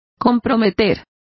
Complete with pronunciation of the translation of endanger.